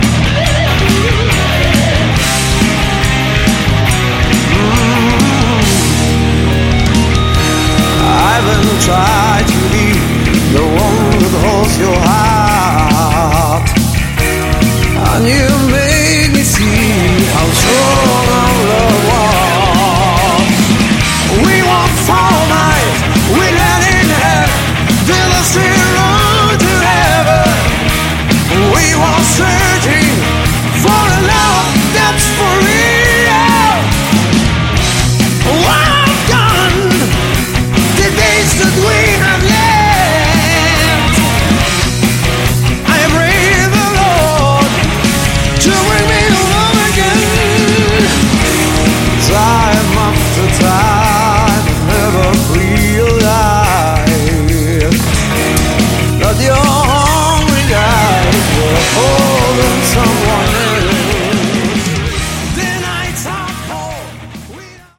Category: Hard Rock
guitars
keyboards
drums, backing vocals